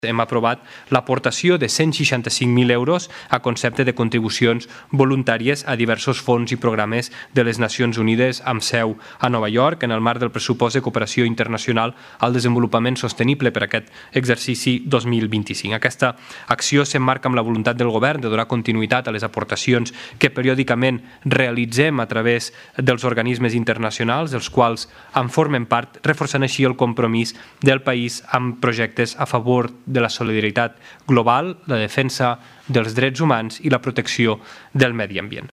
Així ho ha explicat el ministre portaveu, Guillem Casal.